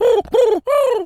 pigeon_call_sequence_03.wav